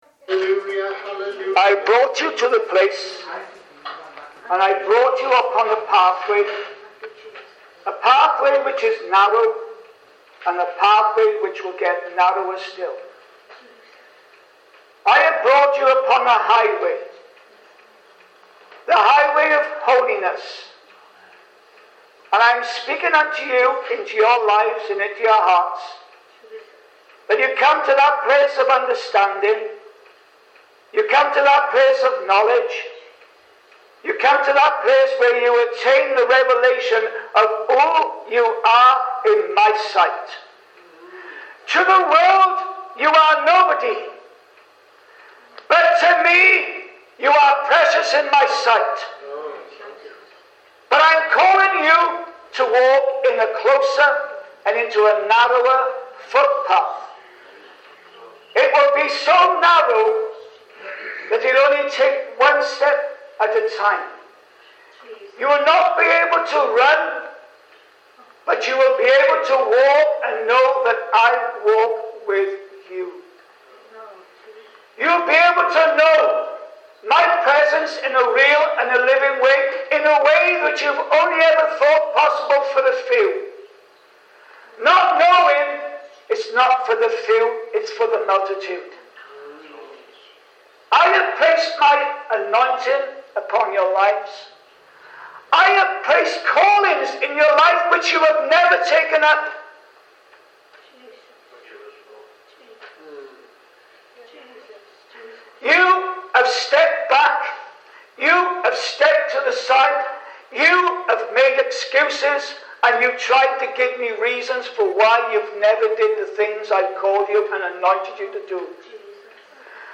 Note: This article is transcribed from a real church recording .